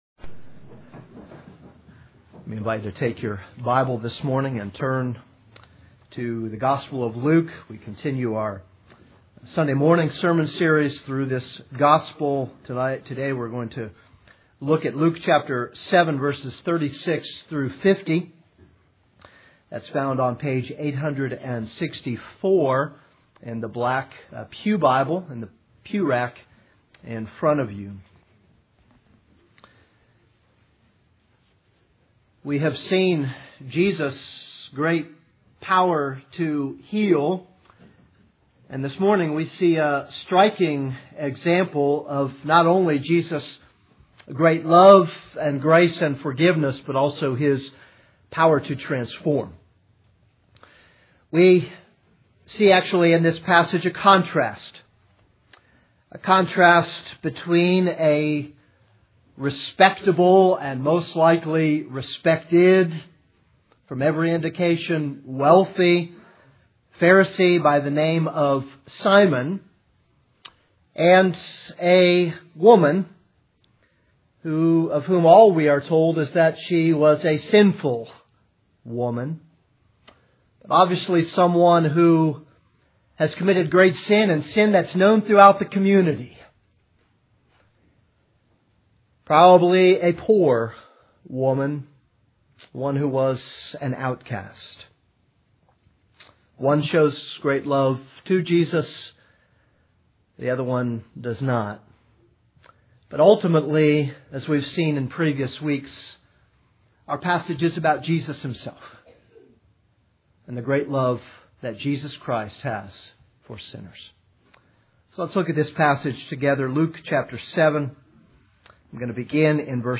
This is a sermon on Luke 7:36-50.